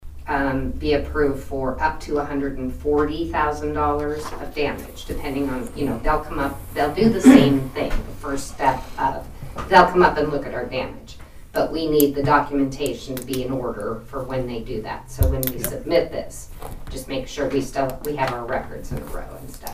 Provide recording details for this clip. The Nowata County Commissioners met for the first time in May on Monday morning at the Nowata County Courthouse Annex.